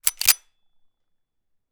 Added weapon resting & weapon jam sounds
fixing_pistol.wav